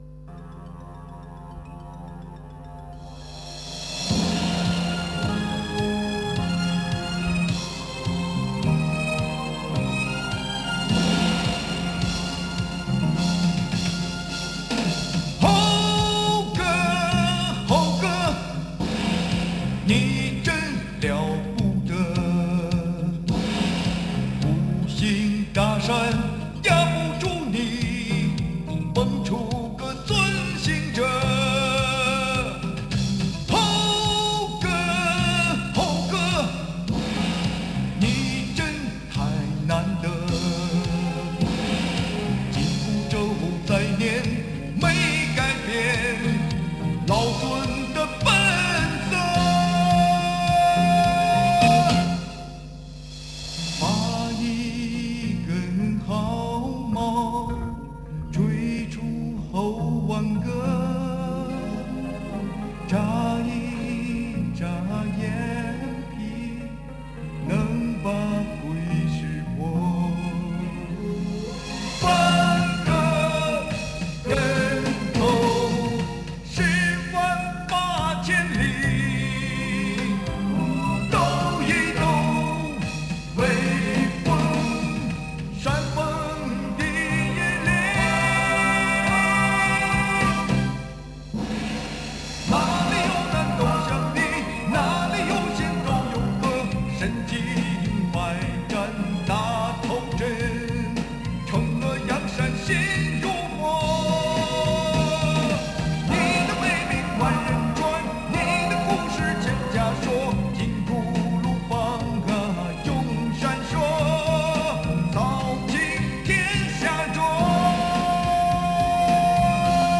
I recorded it myself, so it's in .wav format.